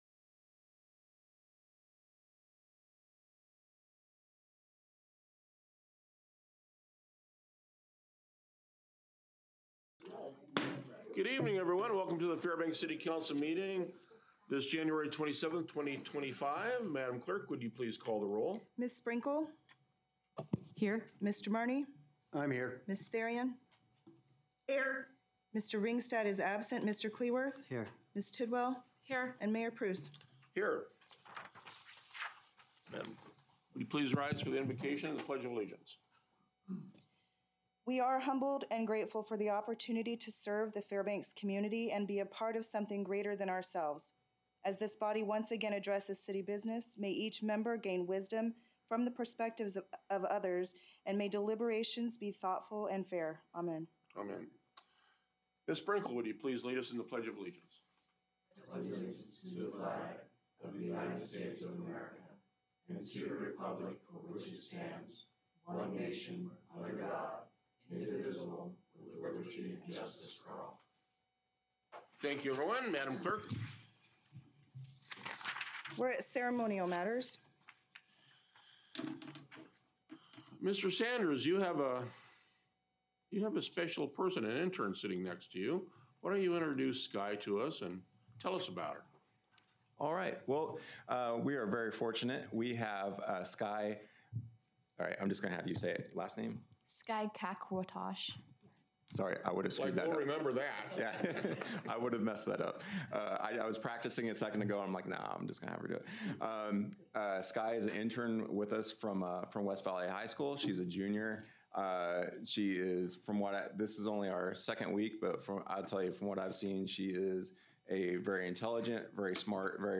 Regular City Council Meeting